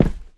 step3.wav